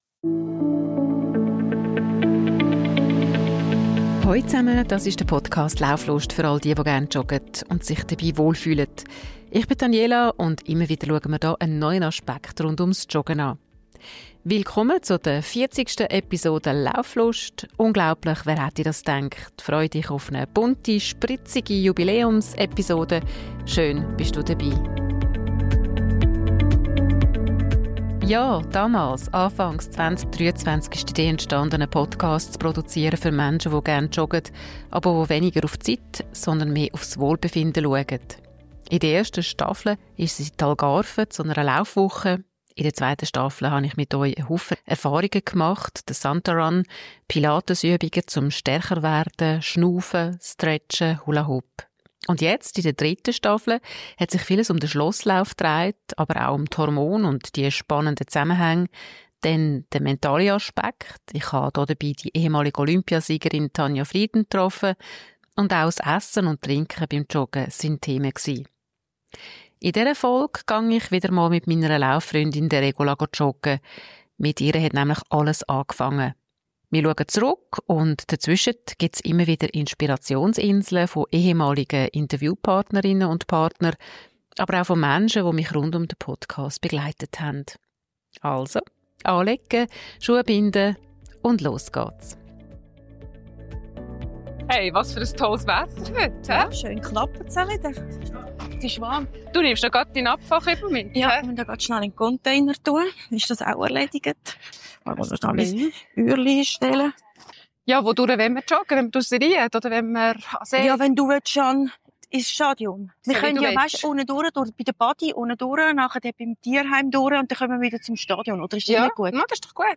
Dazu Stimmen von früheren Gästen und Freund*innen des Podcasts. Joggen ist mehr als Bewegung – es ist Lebensgefühl.